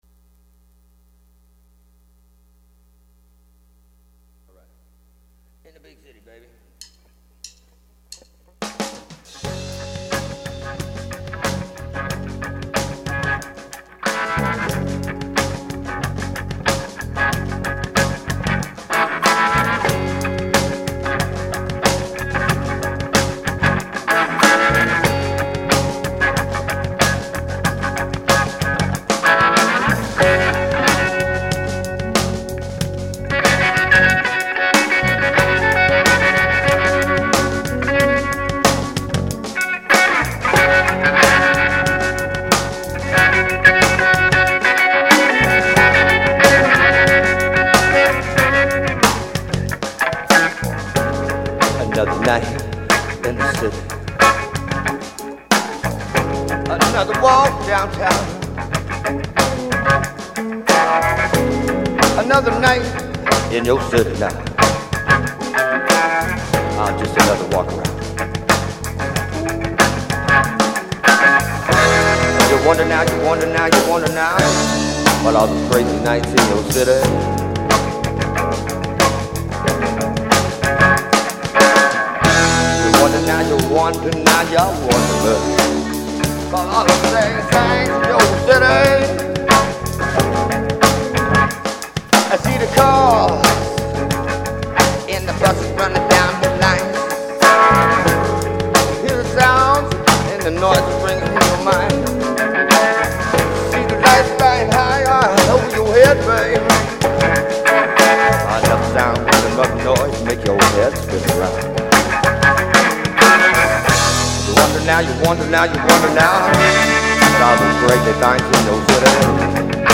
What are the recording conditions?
Live performance Live Audio Live Track